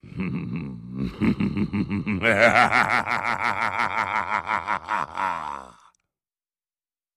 Laughter
Deep evil laugh, male